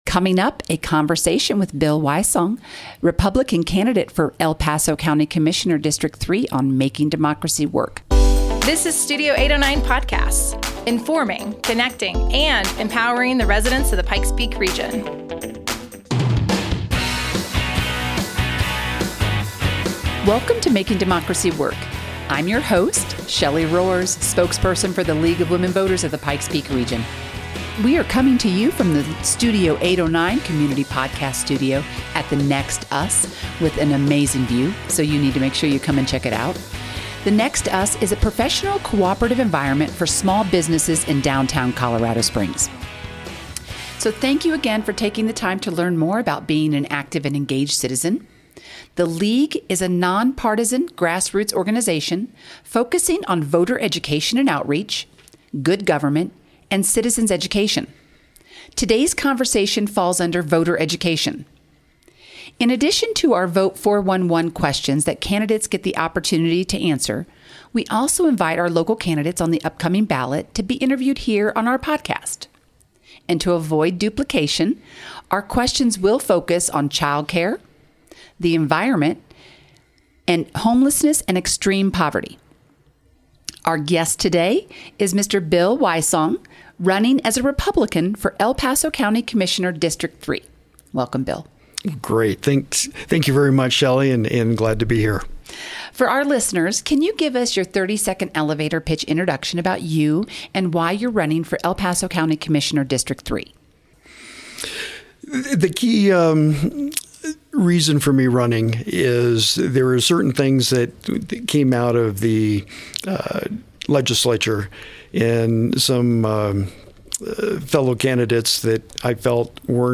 Candidate Interview